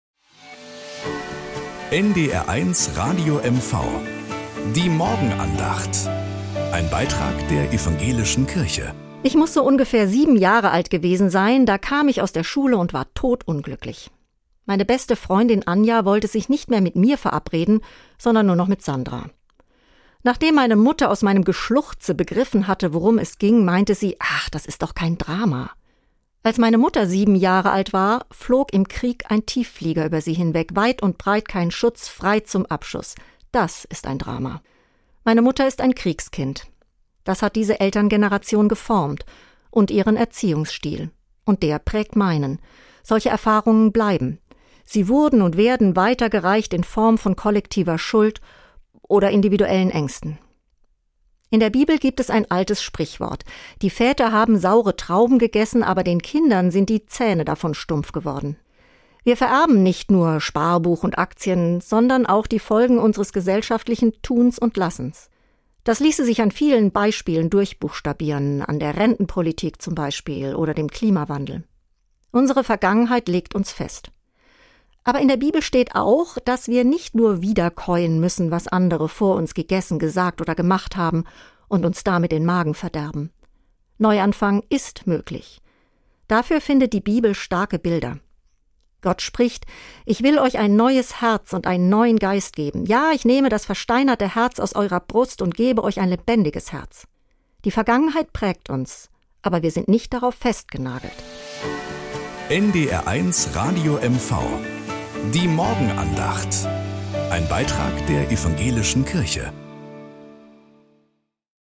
Aktuell und hintergründig - christliche Antworten auf die Fragen unserer Zeit. Die tägliche Morgenandacht auf NDR 1 Radio MV - für Ihren guten Start in den Tag.